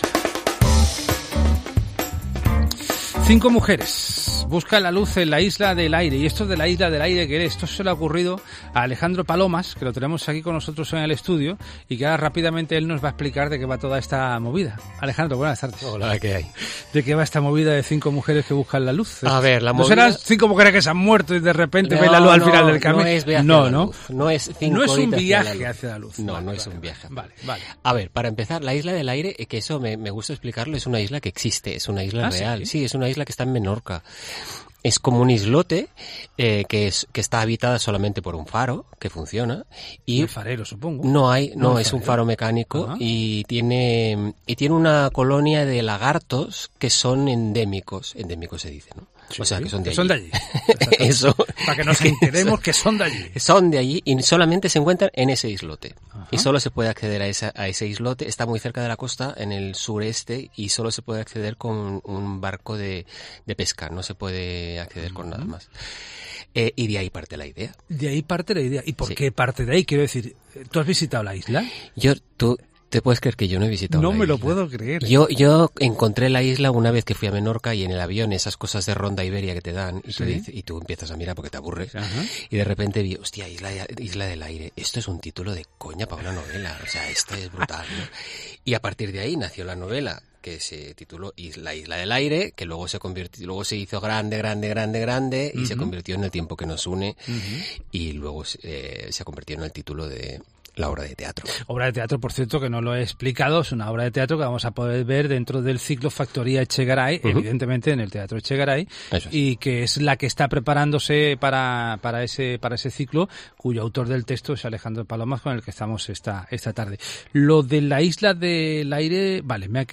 'La isla existe y está en el archipiélago balear, pero aunque la he centrado en ese espacio, lo cierto es que nunca la he visitado' confiesa Alejandro Palomas en Buenas Tardes Málaga.